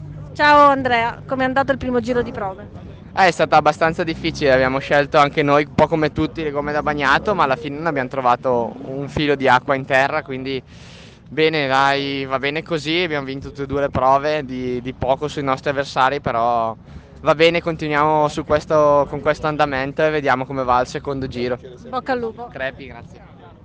Interviste 106° Targa Florio
Interviste dopo la ps.5 di sabato